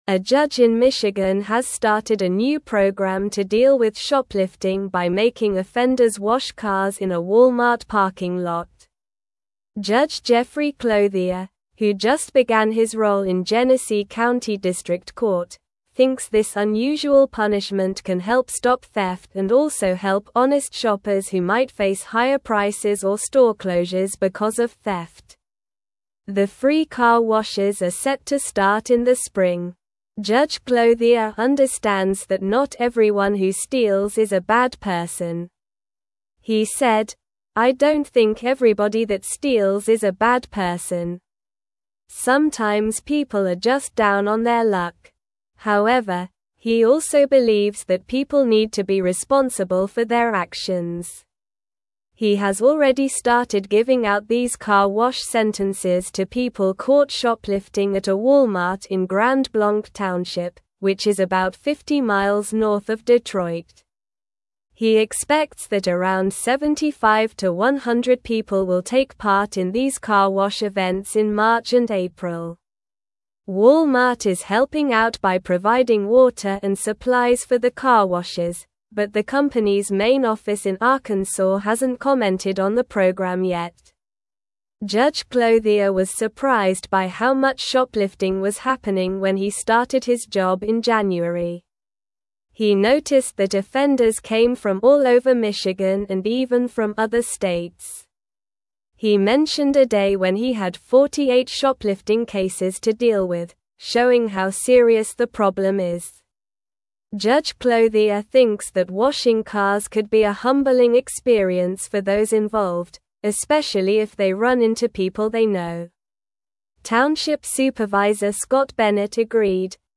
Slow
English-Newsroom-Upper-Intermediate-SLOW-Reading-Michigan-Judge-Introduces-Unique-Community-Service-for-Shoplifters.mp3